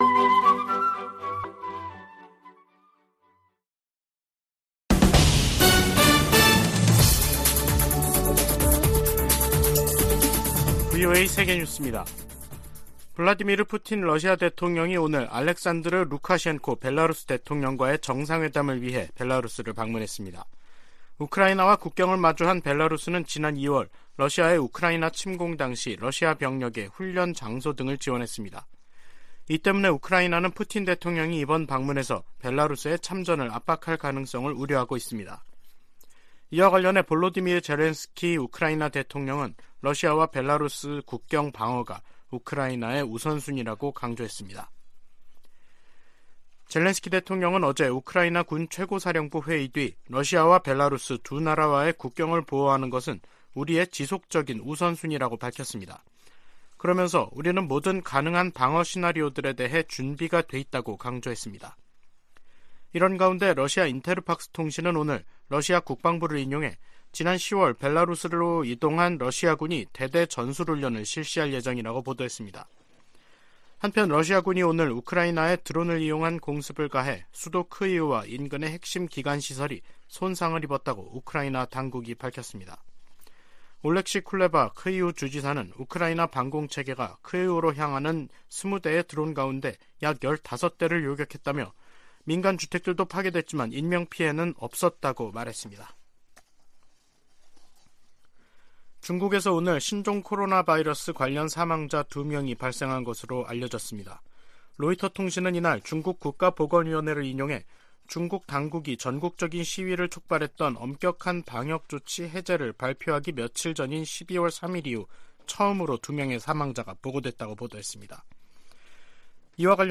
VOA 한국어 간판 뉴스 프로그램 '뉴스 투데이', 2022년 12월 19일 3부 방송입니다. 북한은 내년 4월 군 정찰위성 1호기를 준비하겠다고 밝혔지만 전문가들은 북한의 기술 수준에 의문을 제기하고 있습니다. 미국 국무부는 북한이 고출력 고체 엔진실험을 감행한 데 대해 국제사회가 북한에 책임을 묻는 일을 도와야 한다며 단합된 대응을 강조했습니다.